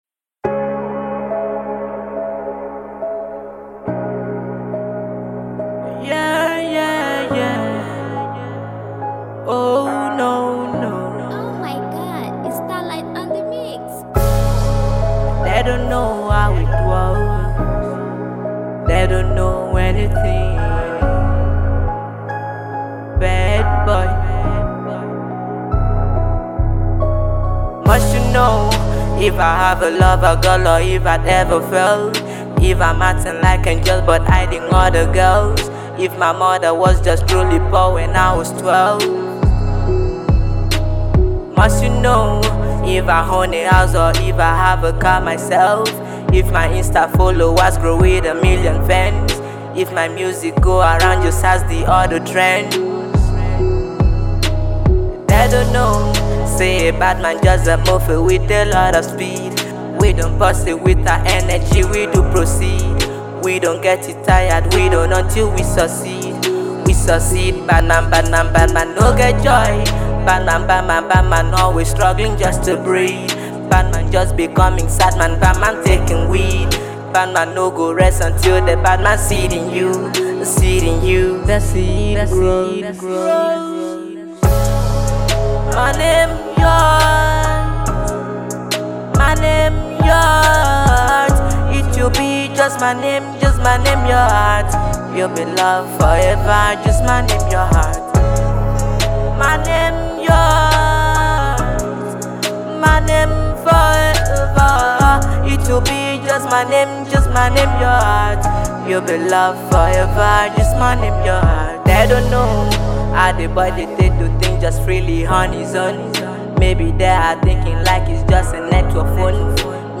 is an Afrobeats singer and songwriter based in Nigeria